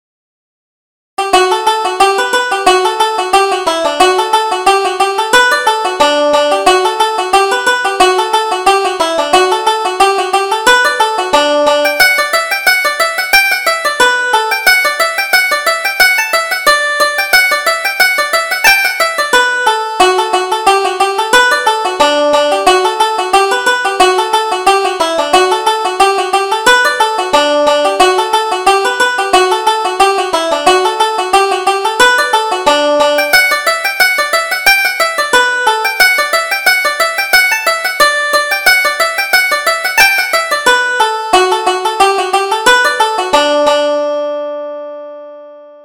Reel: The Maid That Dare not Tell